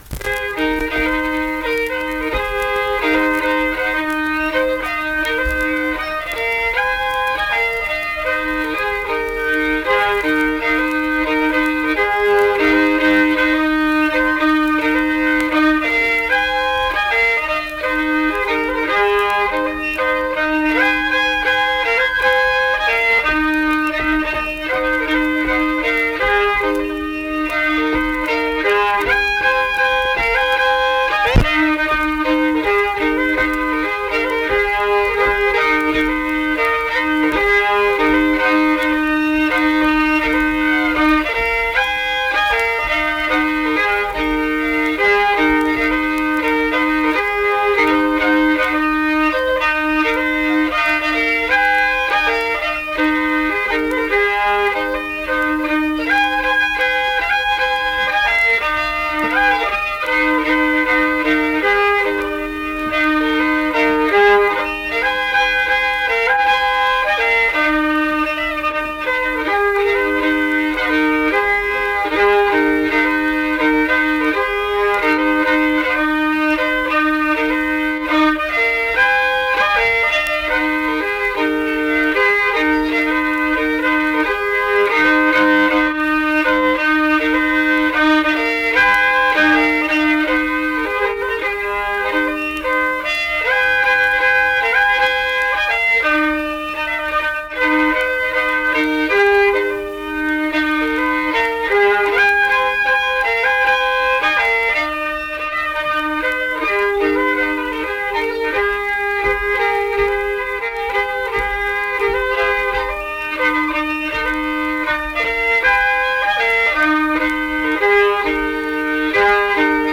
Unaccompanied fiddle music
Instrumental Music
Fiddle
Marlinton (W. Va.), Pocahontas County (W. Va.)